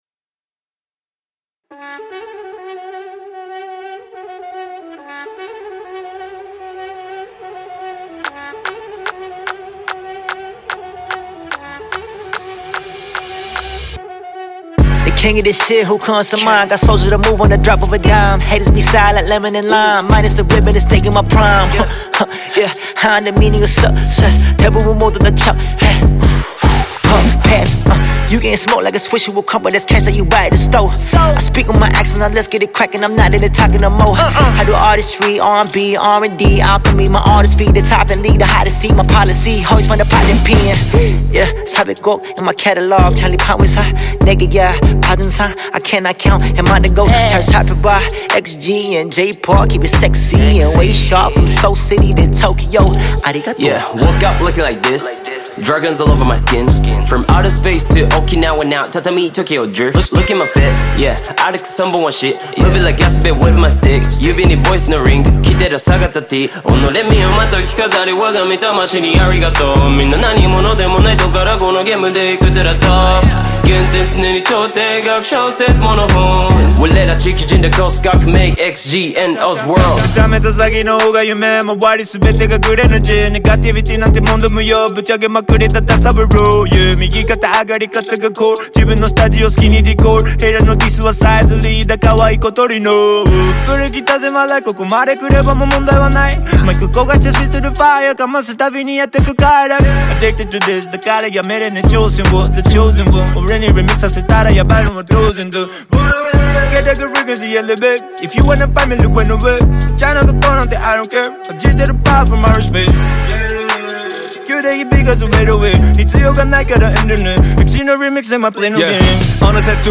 KPop
Label Dance